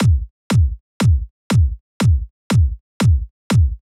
34 Kick.wav